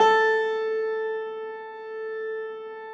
53g-pno13-A2.wav